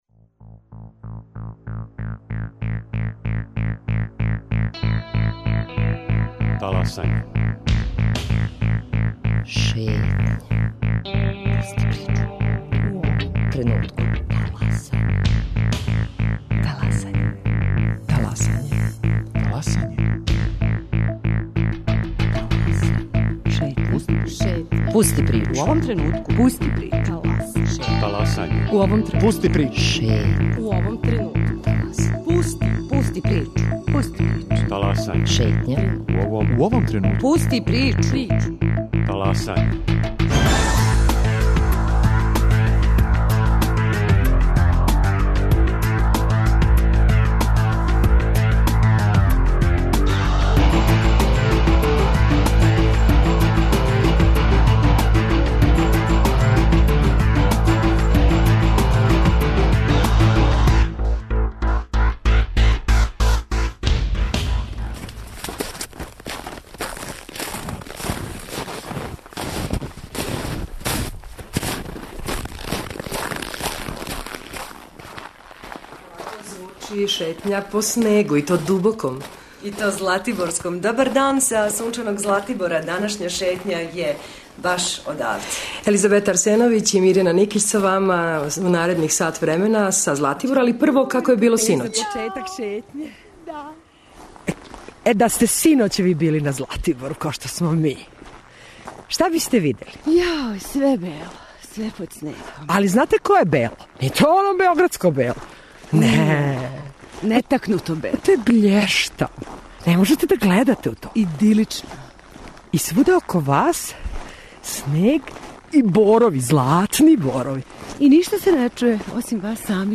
Први сат преподневног магазина Радио Београда 1 овог петка емитујемо са Златибора.